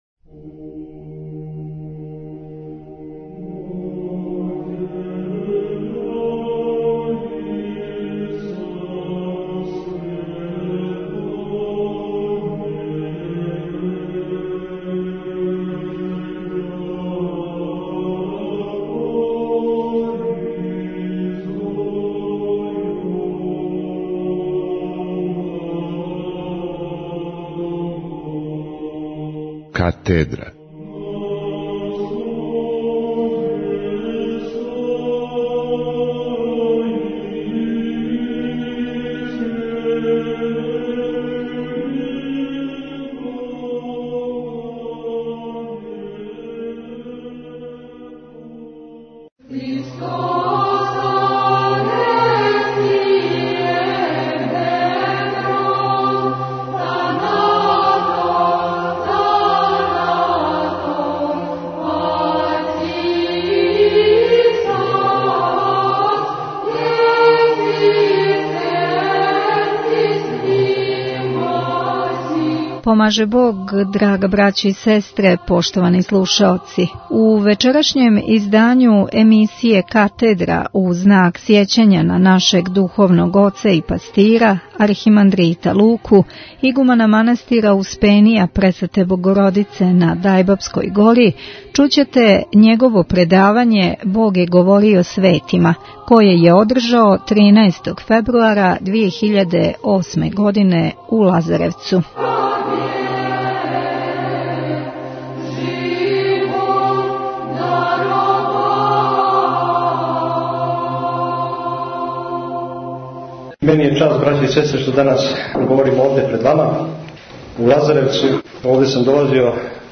Предавање